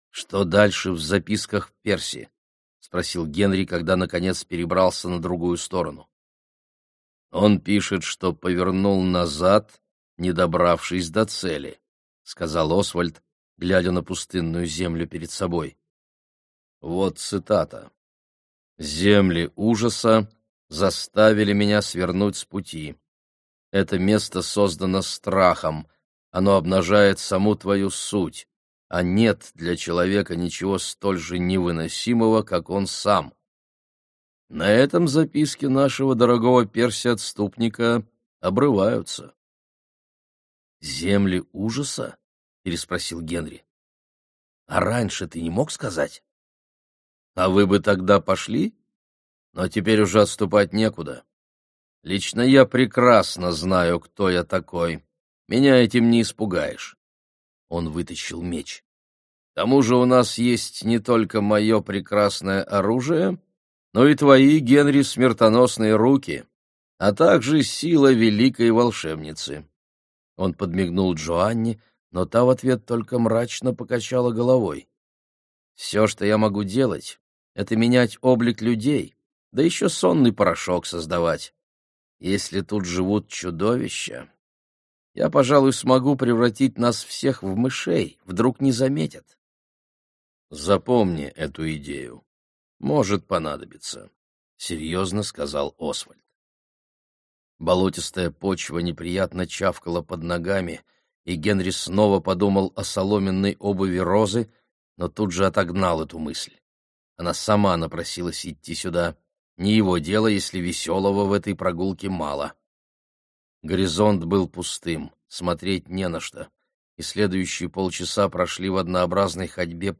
Аудиокнига Дарители. Земля забытых | Библиотека аудиокниг